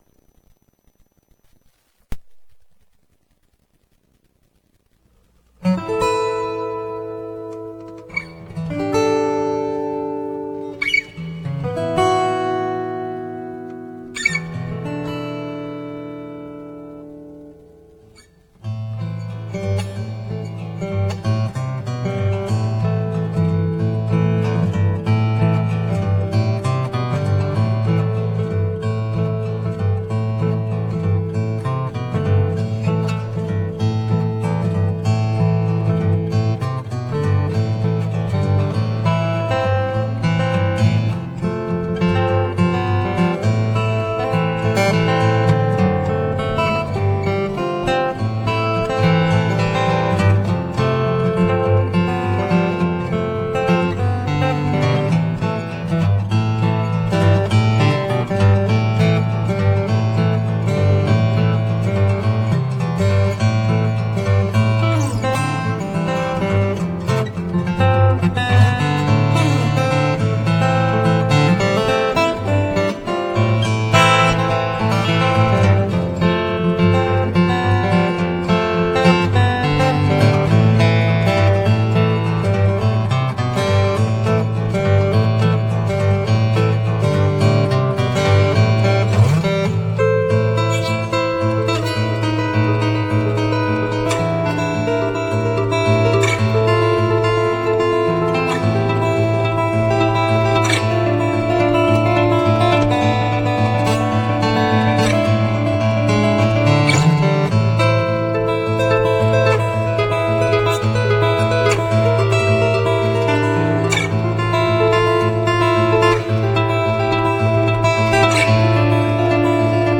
Nocturnals-Inspired Acoustic Guitar Piece!
acoustic guitar piece